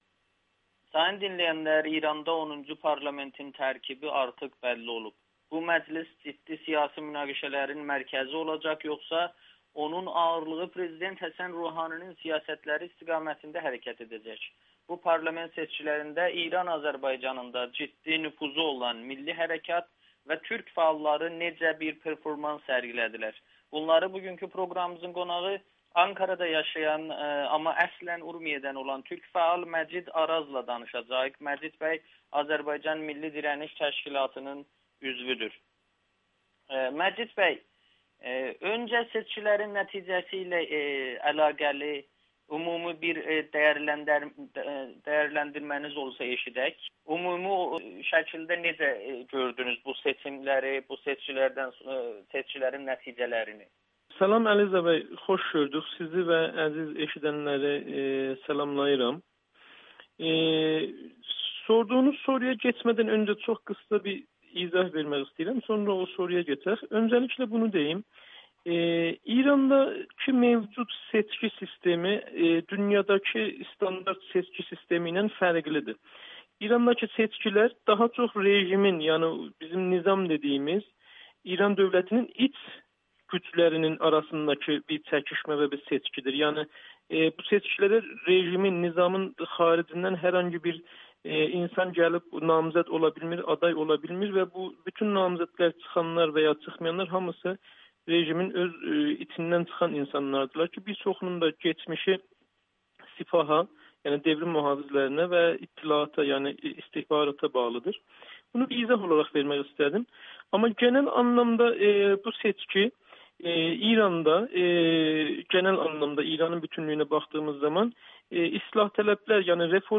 Güneyli fəal Amerikanın Səsinə müsahibədə İranda məclis seçkilərindən sonra parlamentin yeni tərkibi və İran Azərbaycanında seçkilərin nəticələrini dəyərləndirməklə yanaşı seçki kampaniyaları zamanı toplumda və sosial şəbəkələrdə etnik nifrət ifadələrinin yayılmasına özü və təşkilatının münasibətini bildirib.